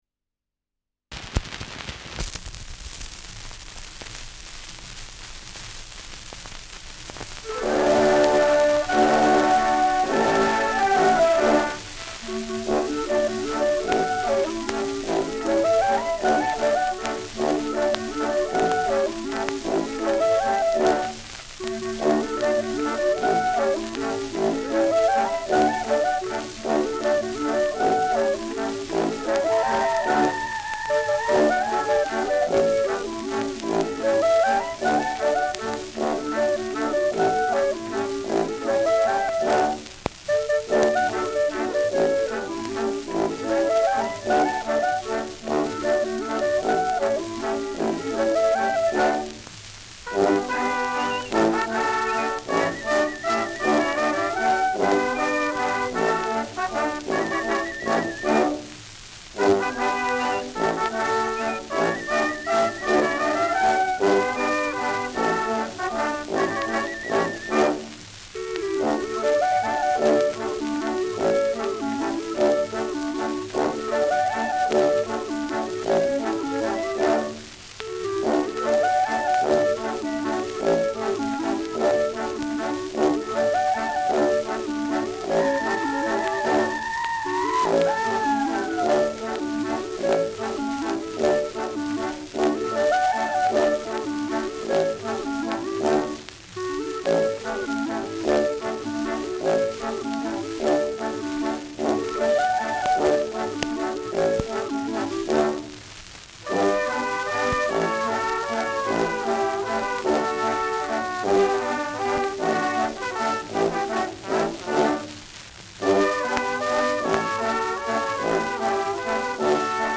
Schellackplatte
[unbekanntes Ensemble] (Interpretation)